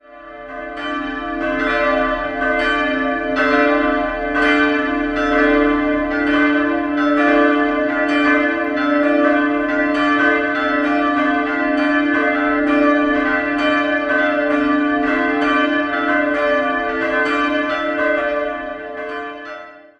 Im Jahr2000 wurde im Langhaus ein gotisches Wandmalereifragment freigelegt, das die bisherige Annahme von der Bauzeit des Langhauses widerlegt. 3-stimmiges Paternoster-Geläute: c''-d''-e'' Die große und die kleine Glocke wurden um das Jahr 1500 in Nürnberg gegossen, die mittlere stammt aus dem Jahr 1972 aus der Glockengießerei Heidelberg.